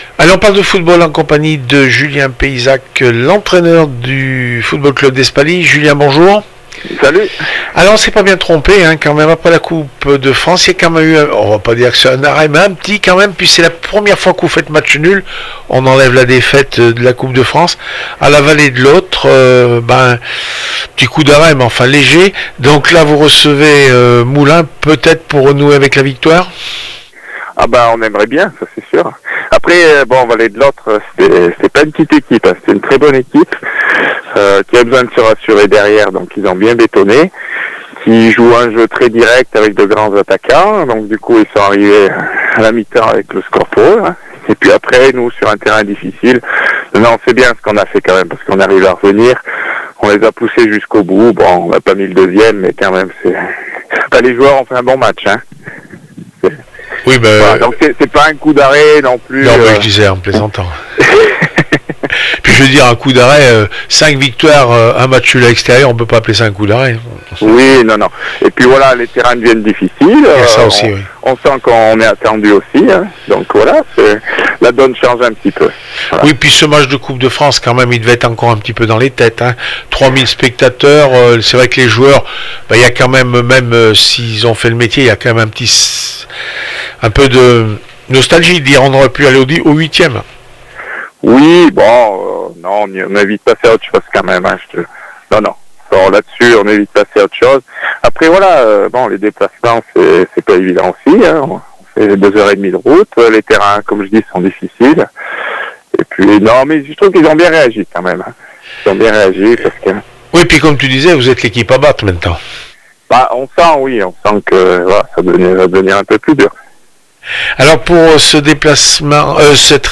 28 novembre 2015   1 - Sport, 1 - Vos interviews, 2 - Infos en Bref   No comments